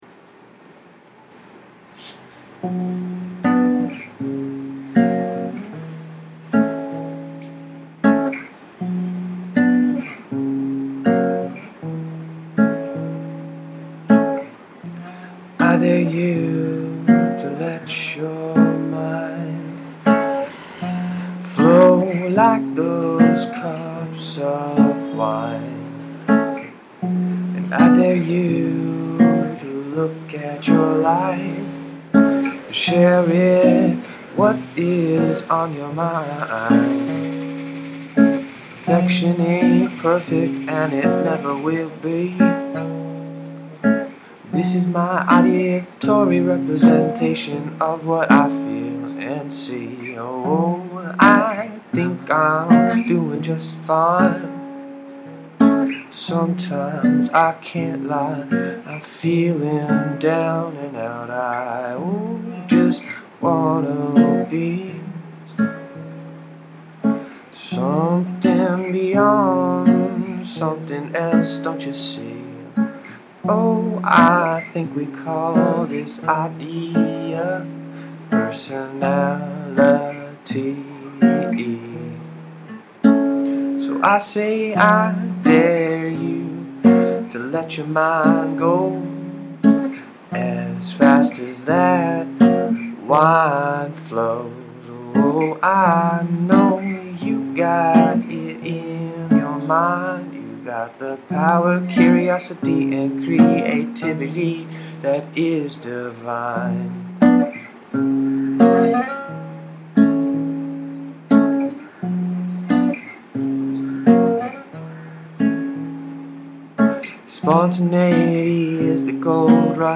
The songs were recorded on my laptop and the guitar and singing were created as it went on… a flow. They are raw, unedited, unscripted songs.